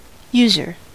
Ääntäminen
US : IPA : [ˈju.zɚ]